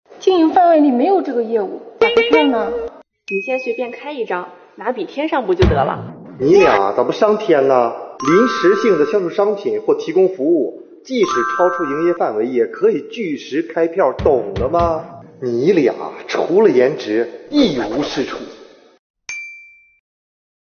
标题: 情景剧丨开发票时，遇到问题这样处理！